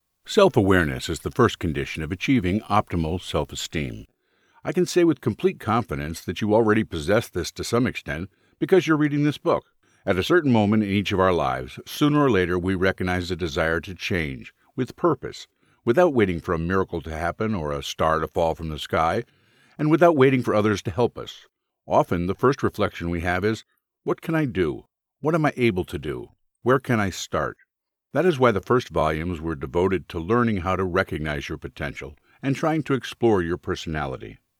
Male
Audiobooks
Narration, Audiobook
0525Audiobook_Demo.mp3